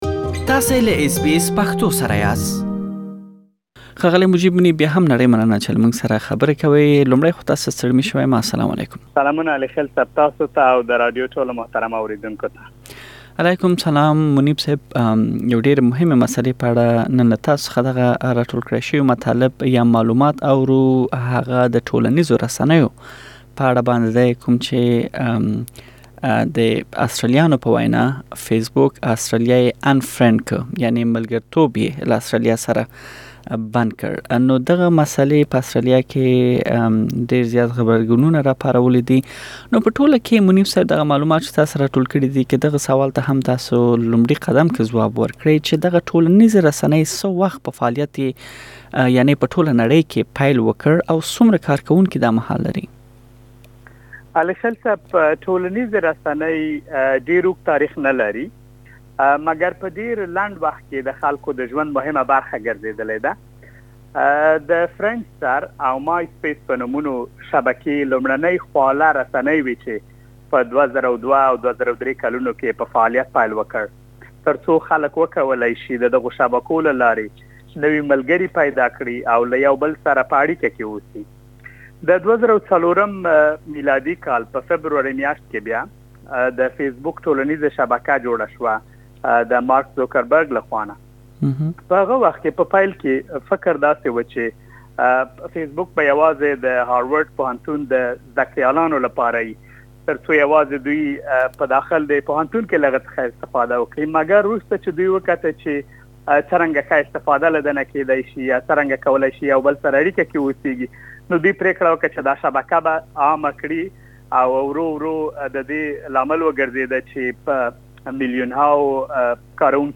پدې معلوماتي مرکه کې به دې سوالونو ته ځواب ومومئ: ټولنیزې رسنۍ اوسمهال څومره کاروونکي لري او څه وخت یې په فعالیت پیل کړی؟